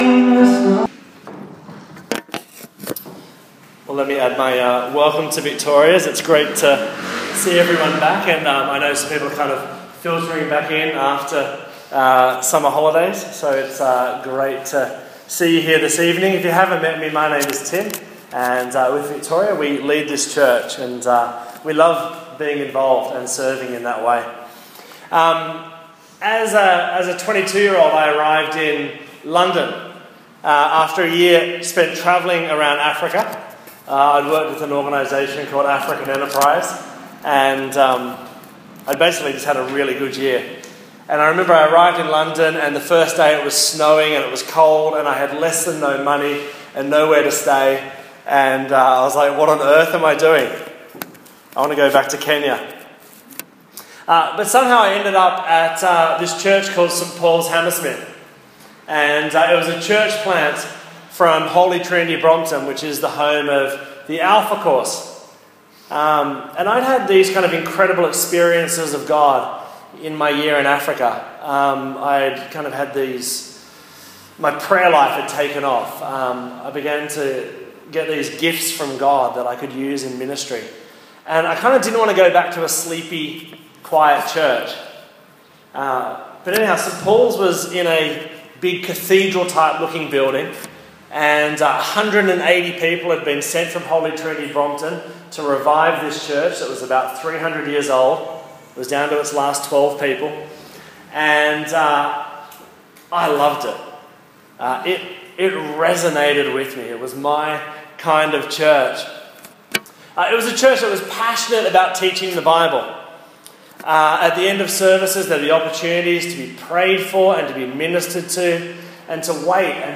Vision Sunday 2013 Sermon
Note: The orchestra playing in the middle of the sermon goes for about 5 minutes. It is of an orchestra playing in a town square which starts with one individual playing and grows until the whole orchestra has emerged to join him.